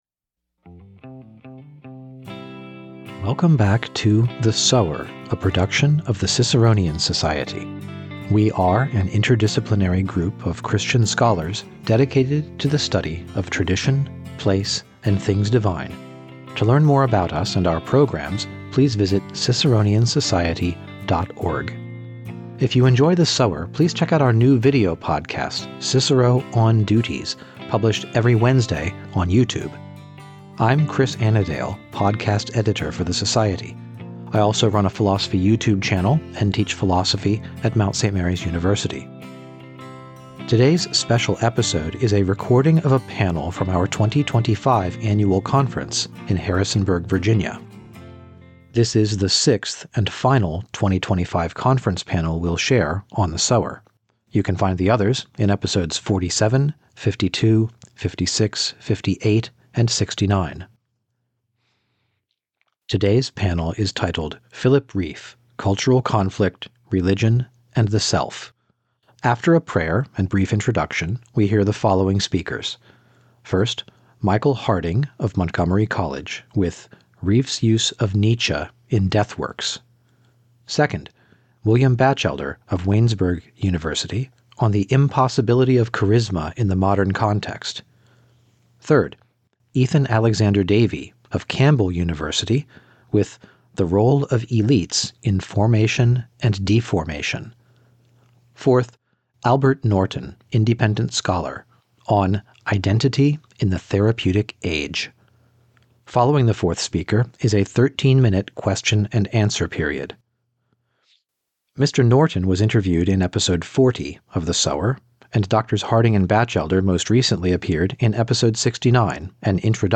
This special episode is an excerpt from a panel at our 2025 annual conference in Harrisonburg, Virginia.
Following the fourth speaker is a 13 minute question and answer period.